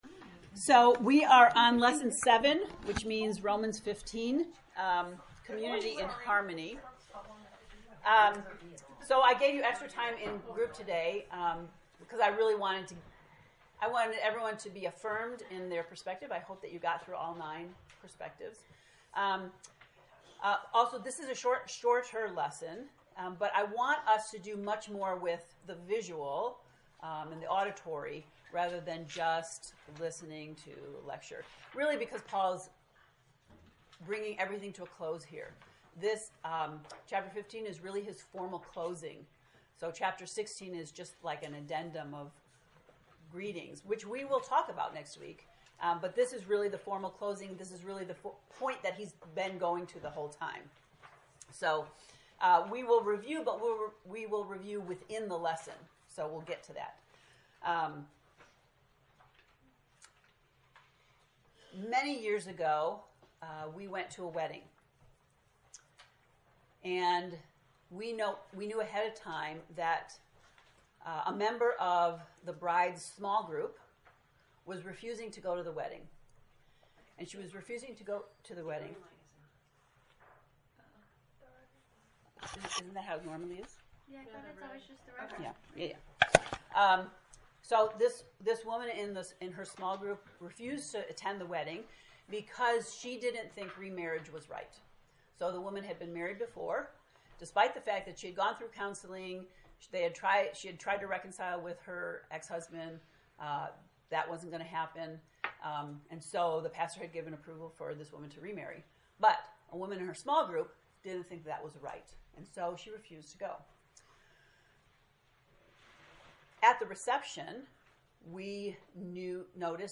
To listen to the lesson 7 lecture “Community in Harmony,” click below: